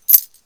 coin.1.ogg